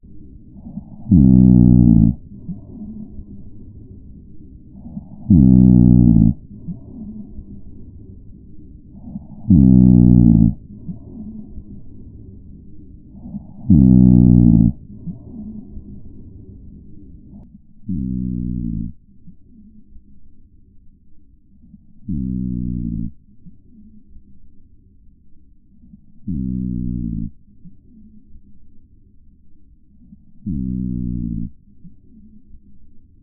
いびきには、重低音〜高音まで幅広い音が含まれています。
・いびきは、設置前の音 16秒 → 設置後の音 16秒
※壁越しの音声のため、音が曇って歪んでいます。